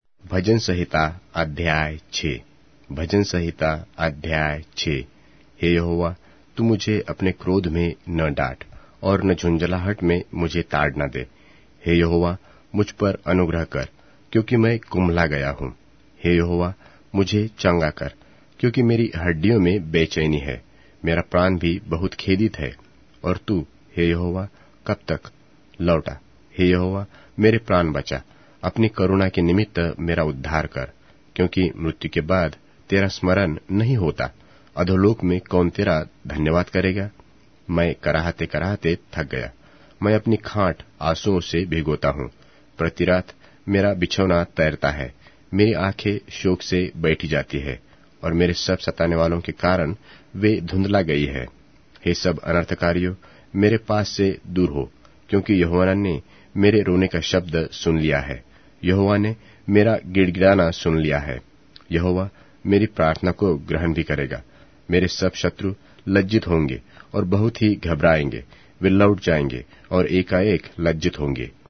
Hindi Audio Bible - Psalms 147 in Ocvkn bible version